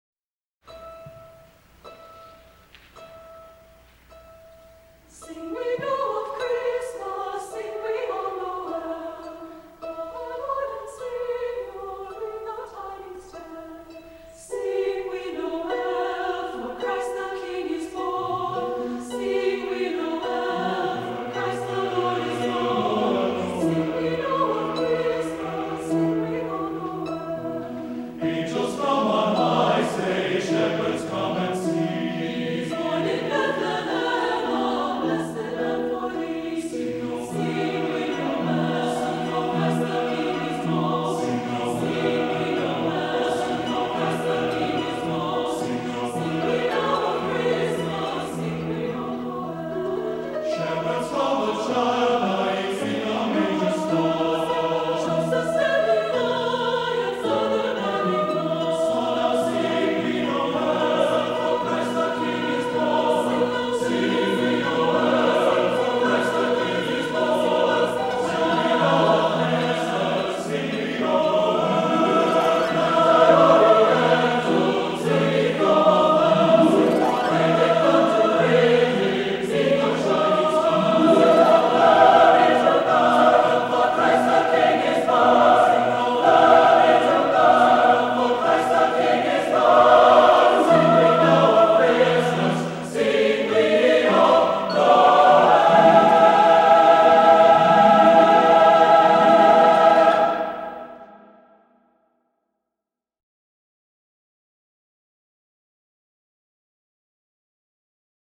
Voicing: SSAATTBB a cappella